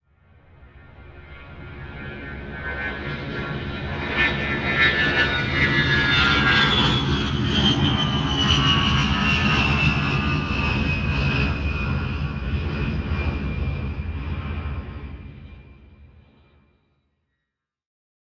airplane-sound-effect